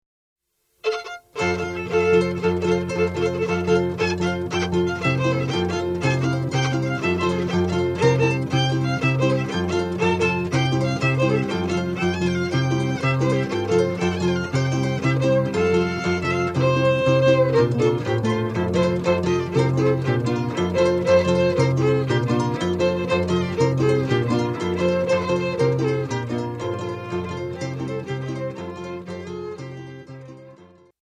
Yaqui pascola music: San Javielpo Chu'kuy Kawi
Rattles made of dried cocoons of giant silk moths are tied around the dancers legs above the ankles and accentuate the rhythmic pattern set by the accompanying violin and harp.
violin
harp